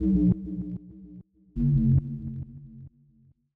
KIN Hollow Bass Riff B-Db.wav